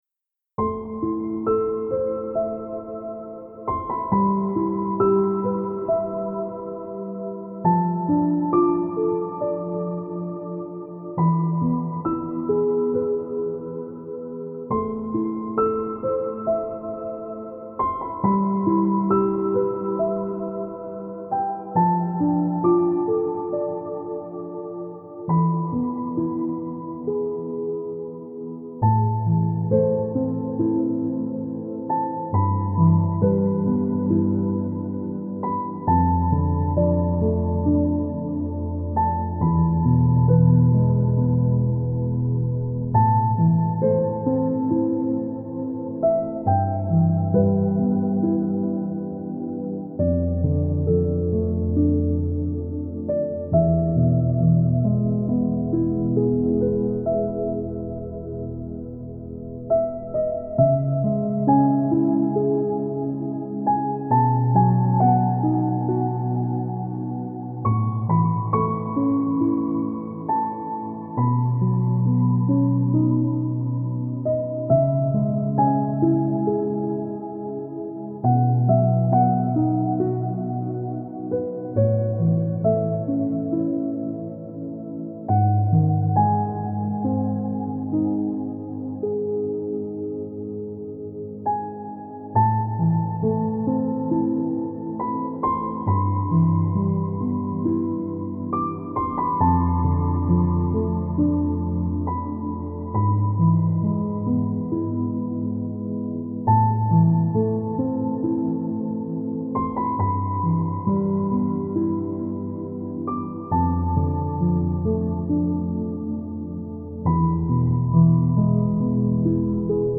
это мелодичная композиция в жанре неоклассической музыки